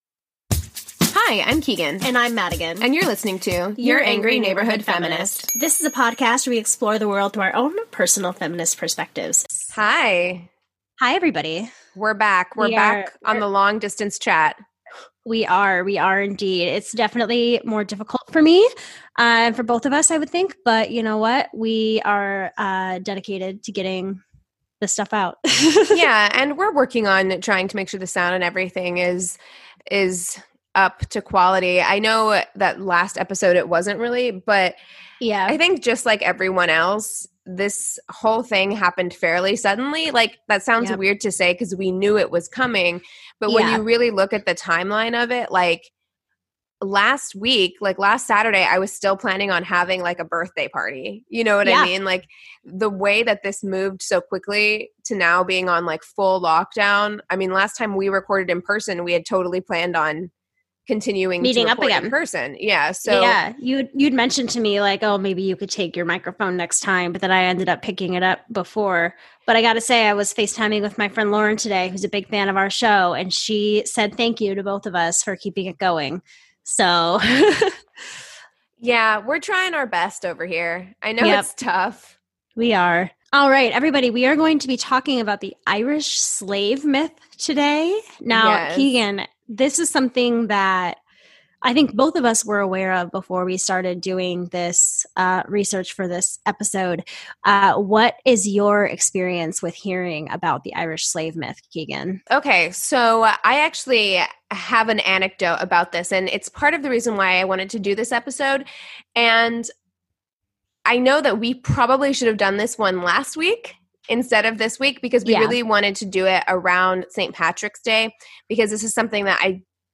Please forgive our changed audio quality! We are adapting to recording "long distance" and are doing our best to find the greatest quality!